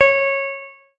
描述：一个用加法合成创造的波型。它有一种廉价的风琴式的音色和一种奇怪的节奏模式。它可以被无缝循环。
标签： 添加剂 无人驾驶飞机 正弦 合成器
声道立体声